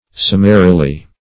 Summarily \Sum"ma*ri*ly\, adv.
summarily.mp3